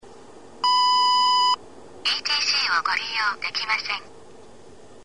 DIU-9000 音声案内　（MP3録音ファイル）
ＥＴＣをご利用出来ませんと発音する。